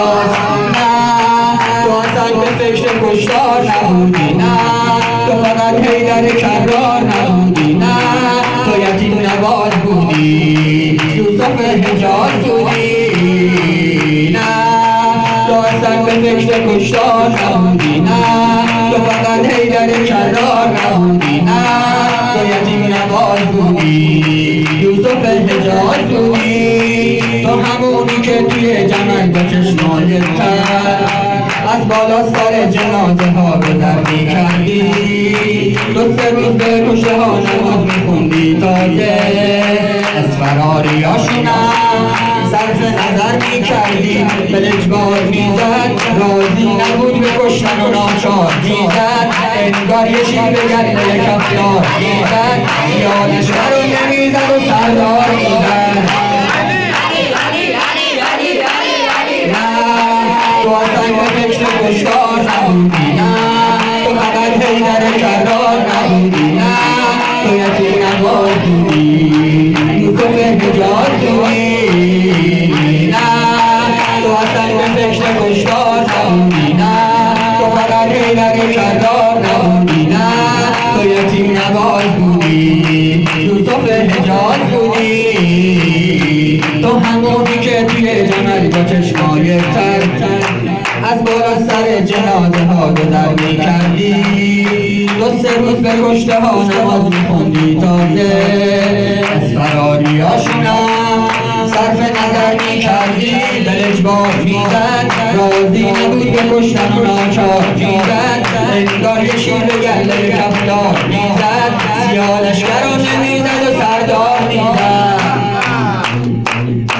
سرود امیرالمومنین امام علی(ع)